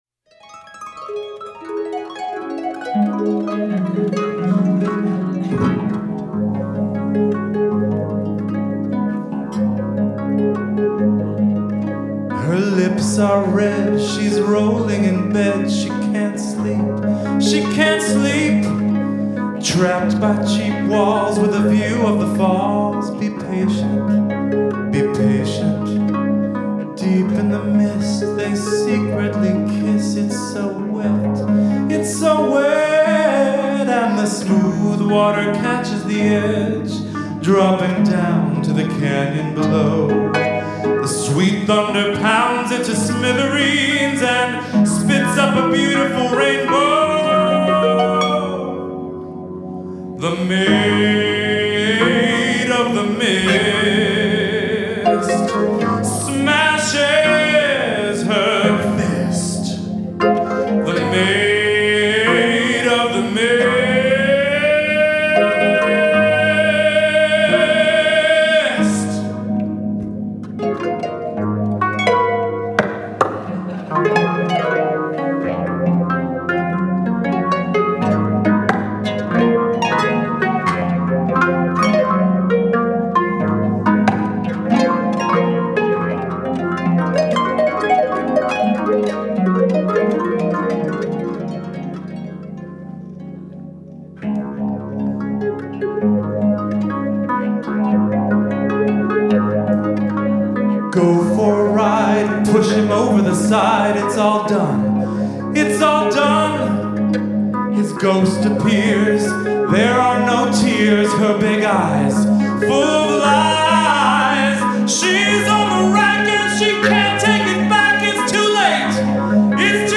Original song, arranged and performed with harpist